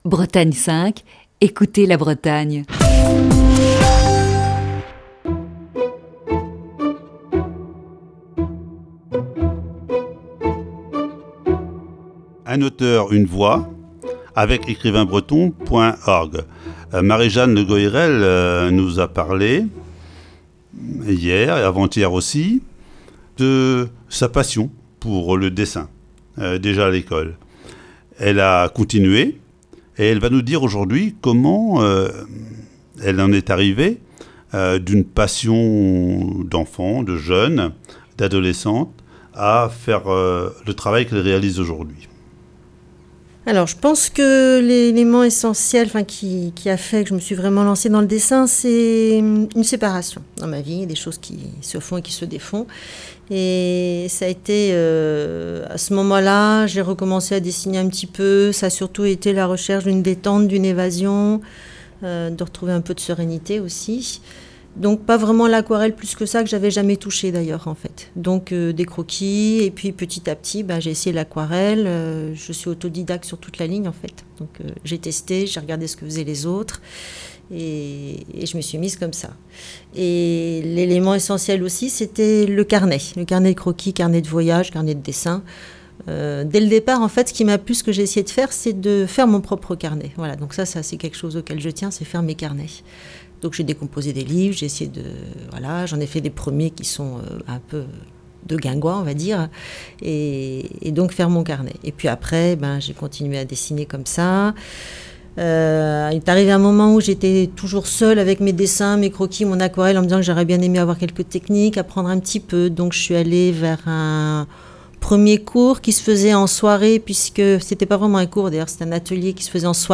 Voici ce mercredi, la troisième partie de cet entretien.